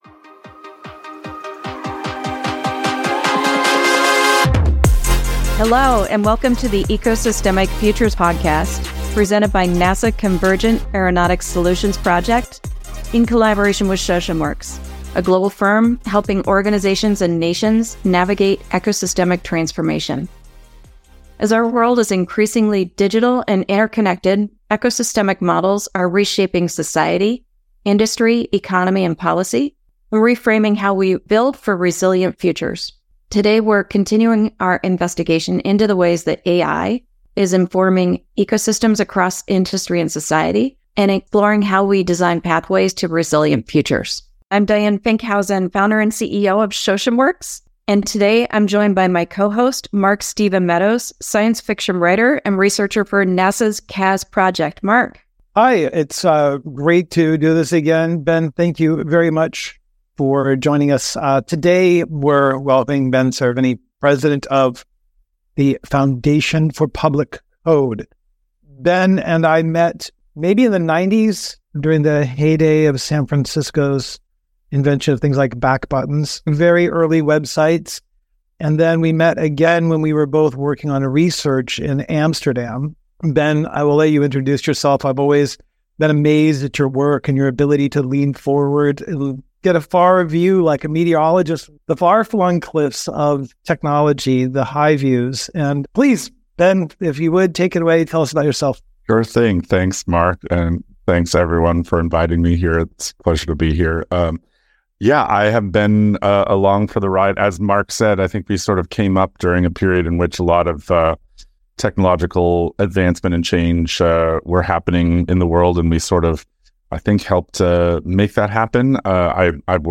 Civilization came together when people agreed on a shared "code" about building communities and cities. Today, some believe there is a need for a new layer of accountable, transparent, and contestable code - to support our digitally-enabled society. Join us for a conversation